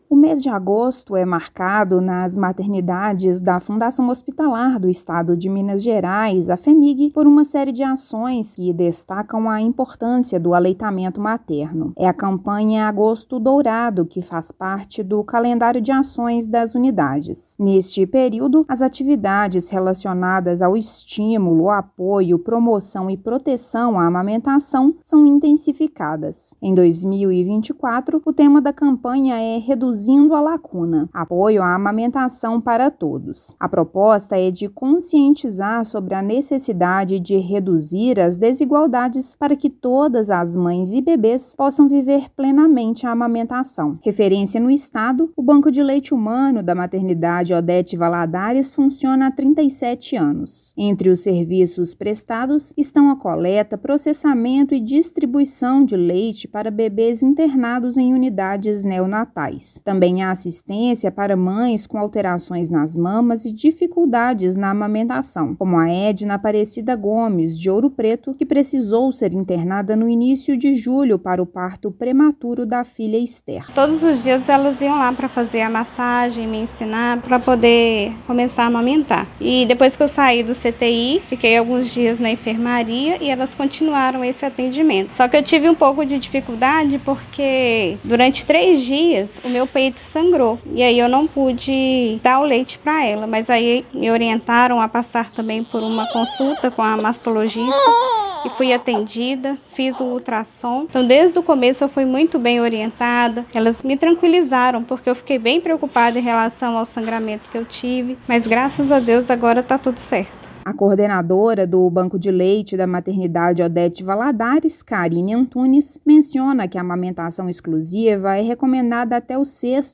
Trabalho das unidades da Fhemig pelo incentivo ao aleitamento materno é certificado por selo do Ministério da Saúde e começa antes do parto. Ouça matéria de rádio.